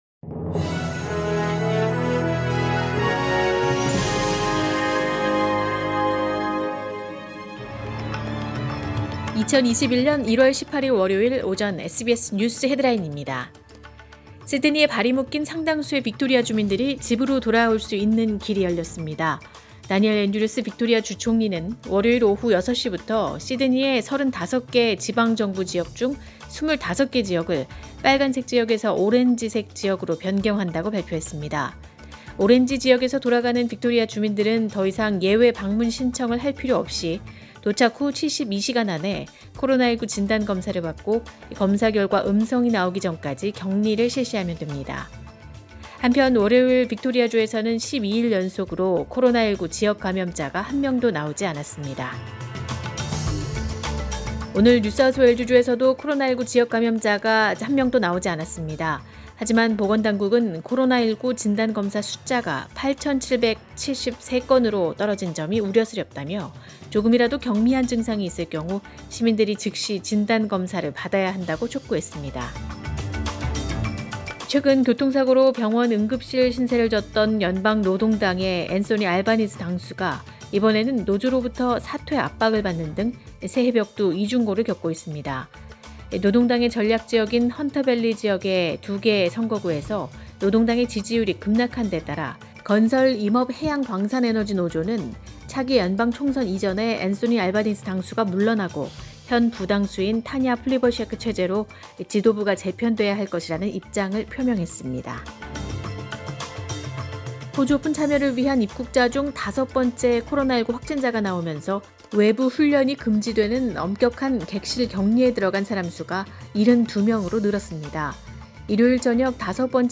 2021년 1월 18일 월요일 오전의 SBS 뉴스 헤드라인입니다.